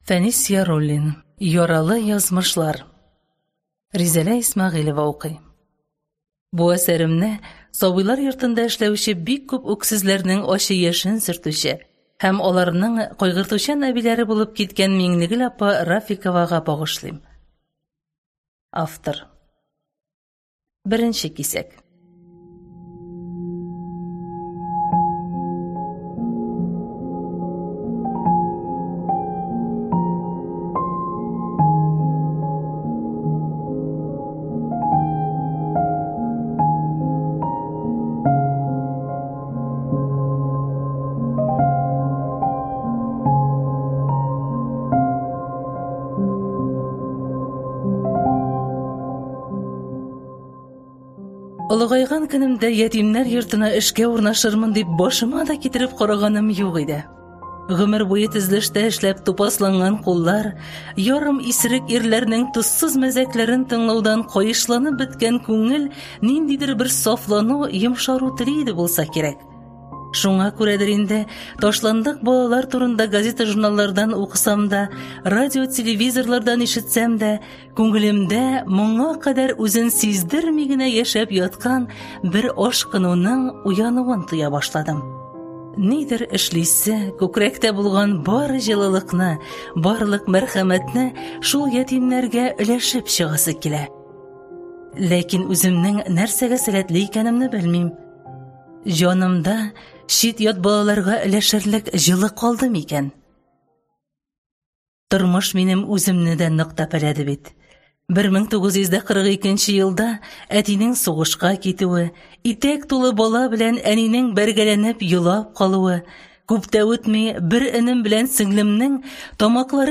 Аудиокнига Яралы язмышлар | Библиотека аудиокниг